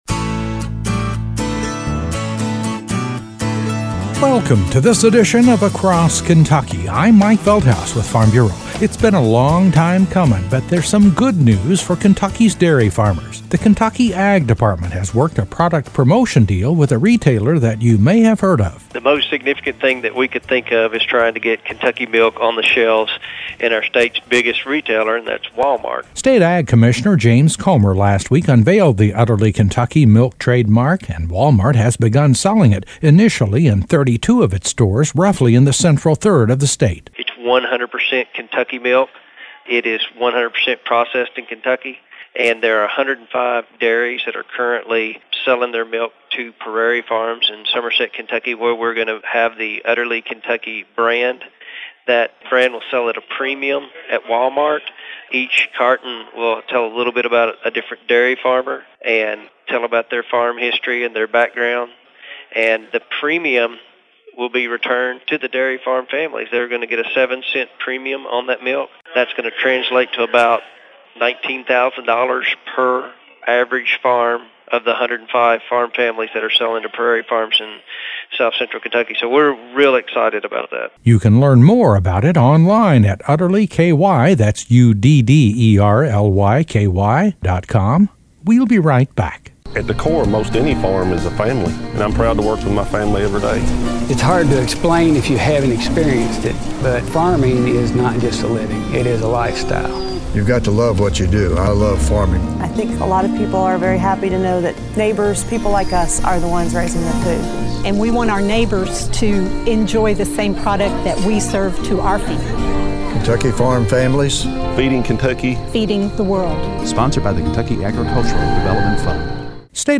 A special report on a Kentucky Proud project involving dairy farmers, the Ky Ag Department, Wal-Mart and Prairie Farms Dairy of Somerset. Kentucky Proud has worked a deal where a major region of Wal-Mart stores have begun selling UdderlyKy milk products and a portion of sales will go back to Kentucky dairy farms.